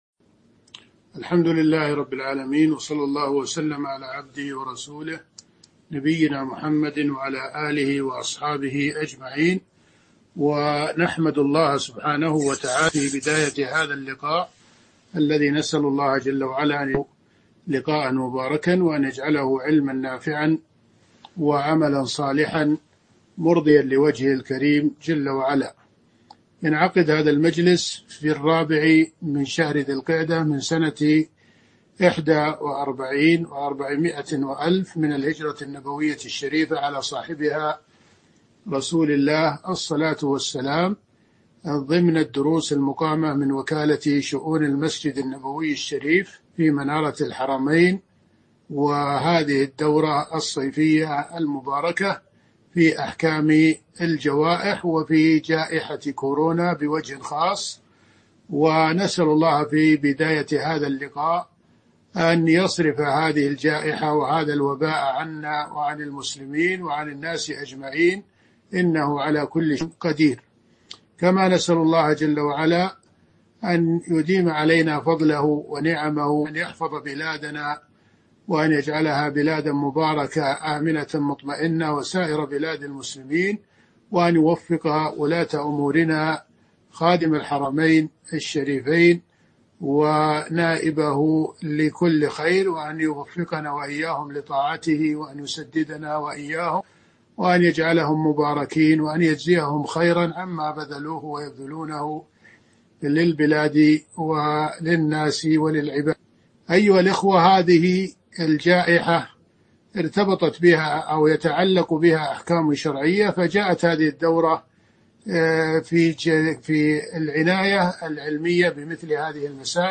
تاريخ النشر ٤ ذو القعدة ١٤٤١ هـ المكان: المسجد النبوي الشيخ